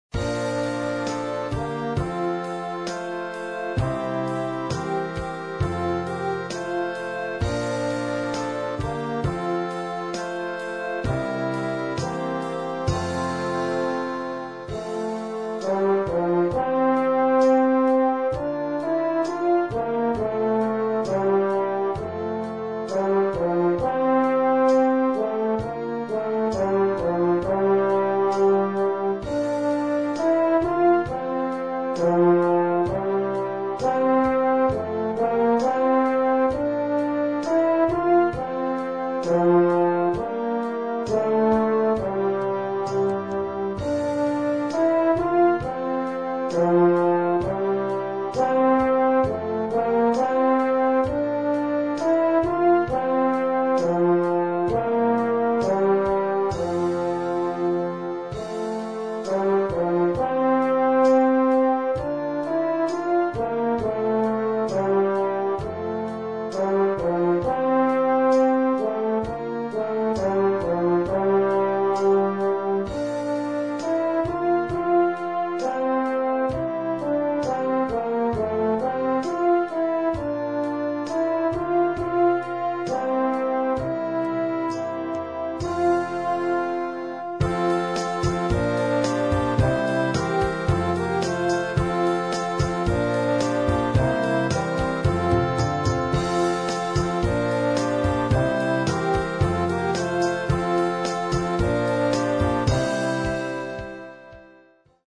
Horn solo & Orchester.
Noten für flexibles Ensemble, 4-stimmig + Percussion.